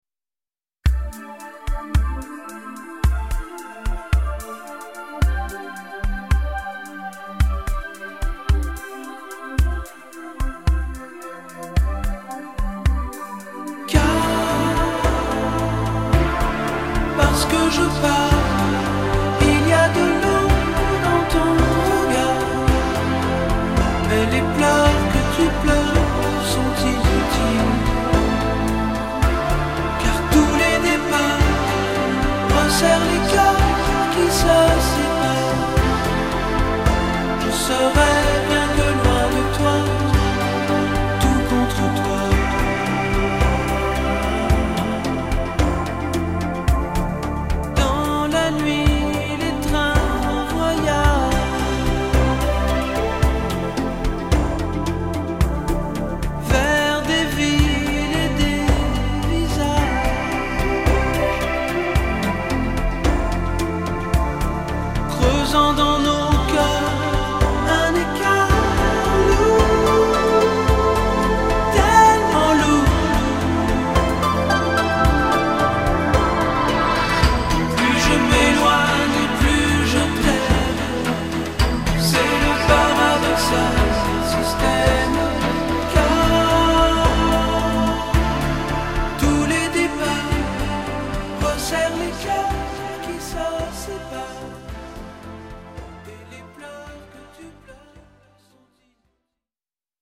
tonalité DO majeur